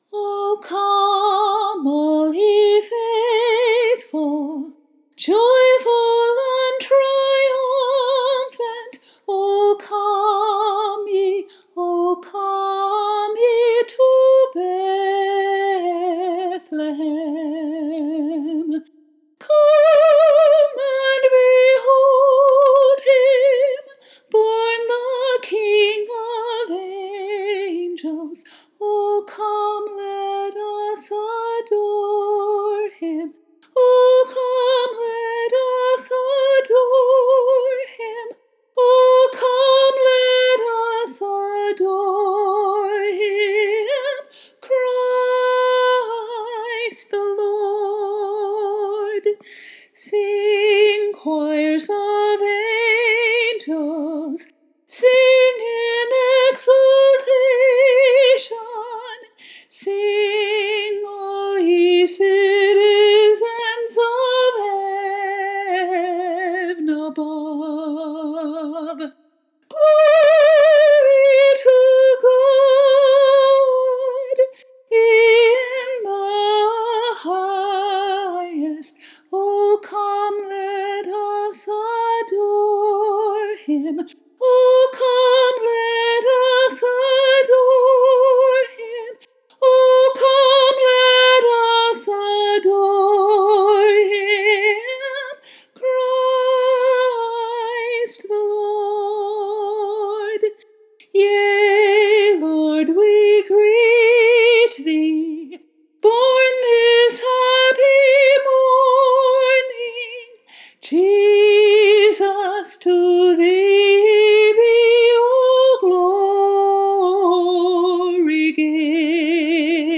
I’d be perfectly content to keep belting those out, right on through January and on into June and July — a cappella, even.
Here’s me singing “O Come, All Ye Faithful.”
Here are the lyrics to this beautiful old Christmas hymn, first published in 1767.